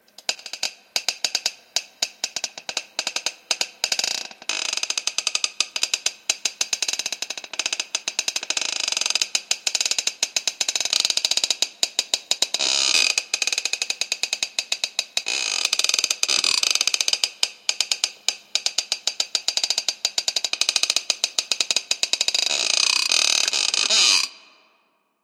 Звуки скрипа кровати
Продолжительный скрип кровати